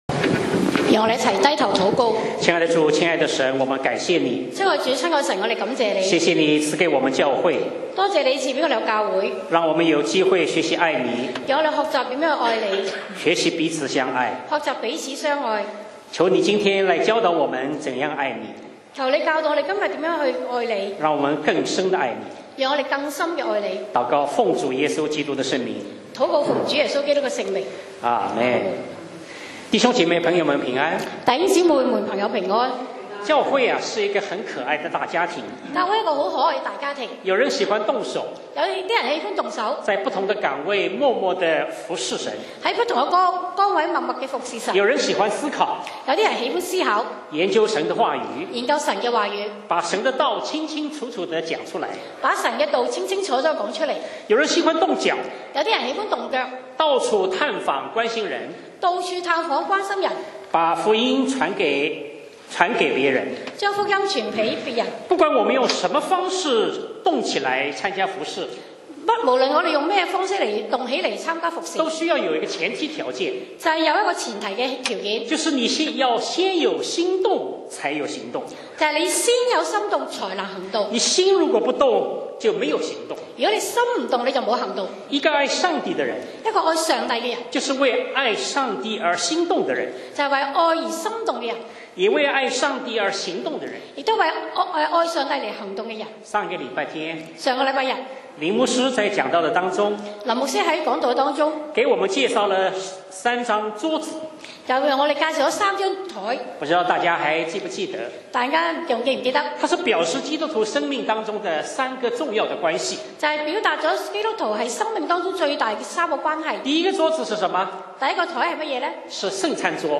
講道 Sermon 題目 Topic：为爱心动，为爱走动 經文 Verses：罗1：8-15。